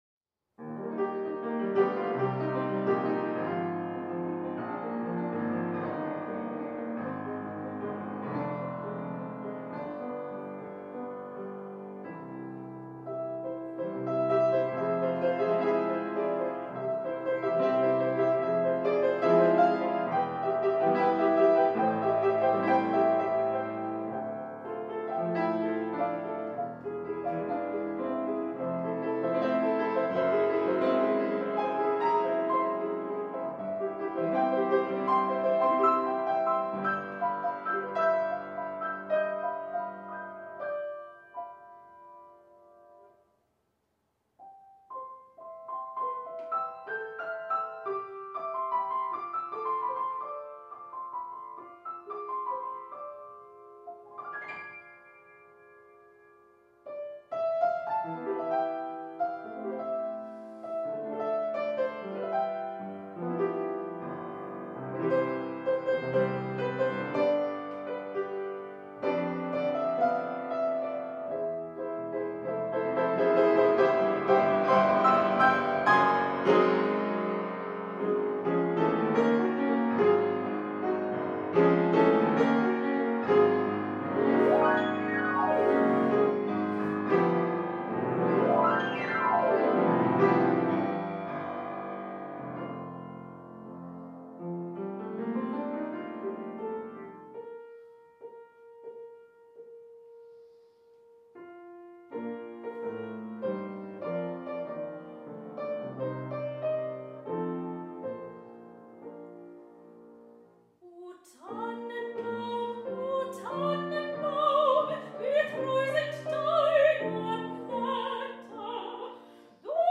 Instrumentación: Soprano, tenor y piano.